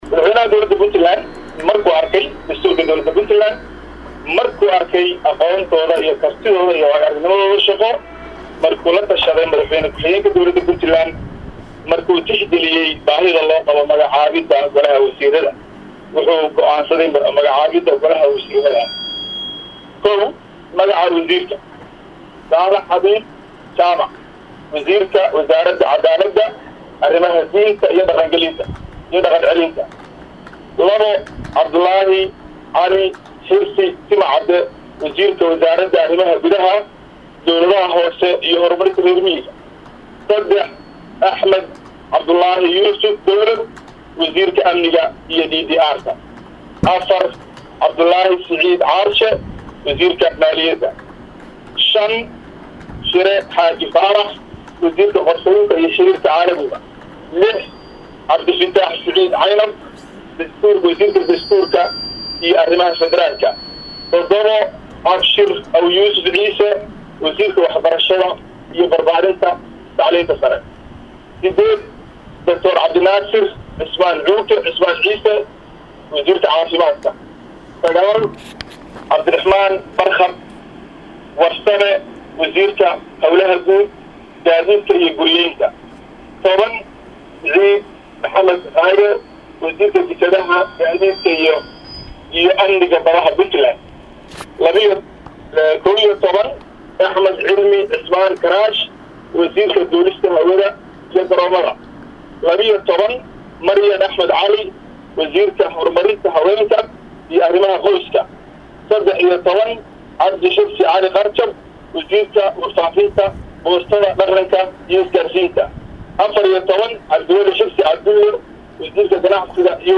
Madaxweynaha dowlad goboleedka Puntland Cabdweli Maxamed Cali oo goor dhow isugu yeeray saxaafada Madaxtooyada magaalada Garowe ayuu ku dhowaaqay gole wasiiro oo ka kooban konton xubnood.